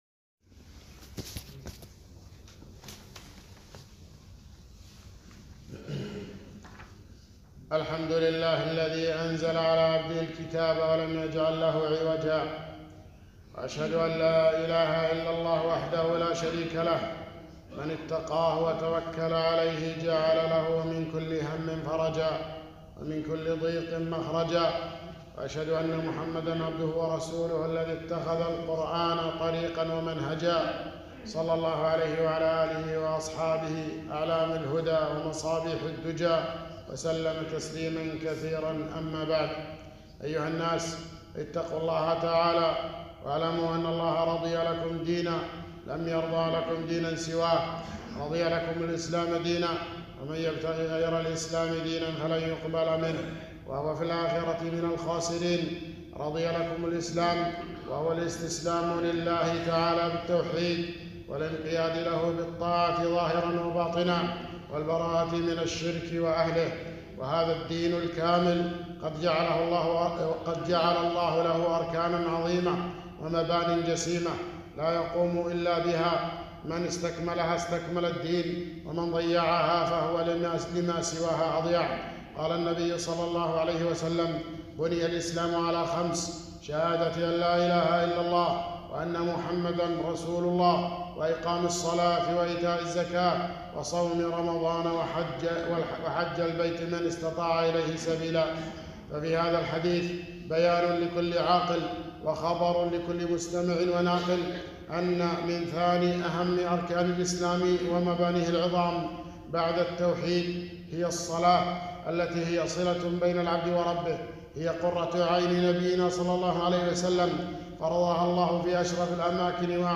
خطبة - التحذير من التهاون في الصلاة